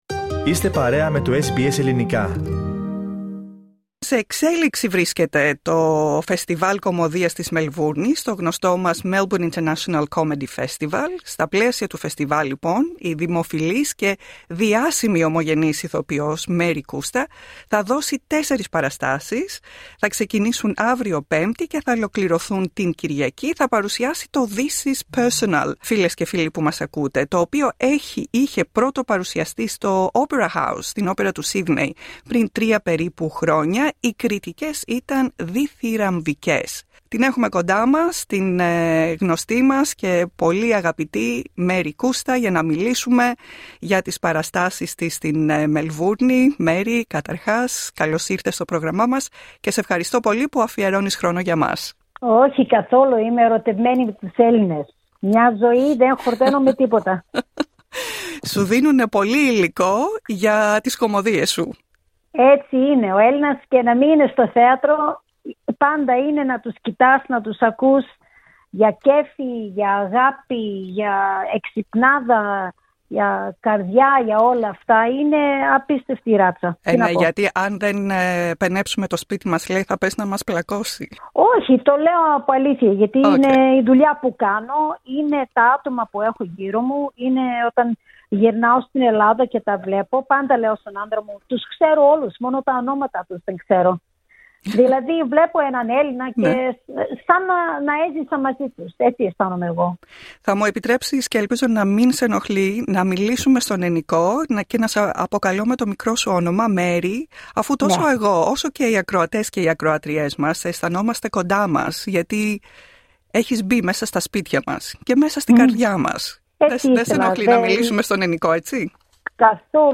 Ms Coustas said that laughter and ability to find the comic element in difficult situations is what gives her - and all of us - courage and hope. Listen to Marie Kousta's interview by clicking on the icon next to the photo.